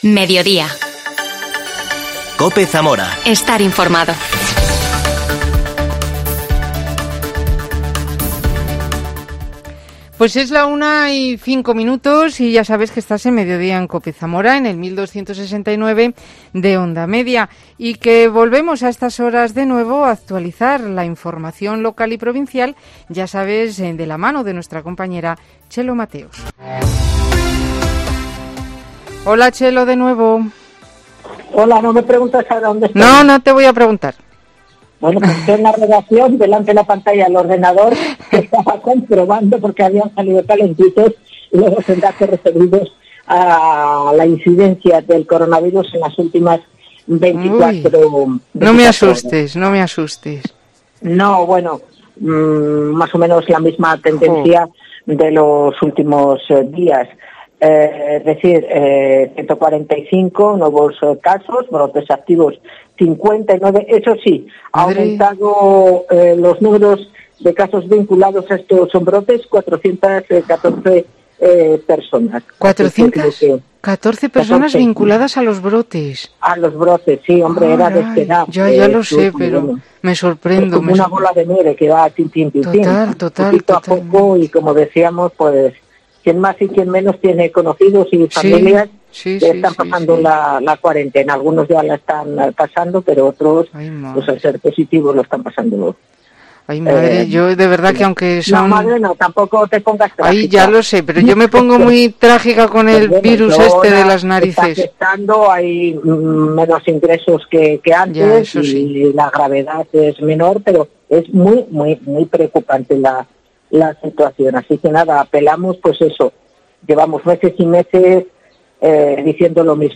Entrevista a la cantaora de Flamenco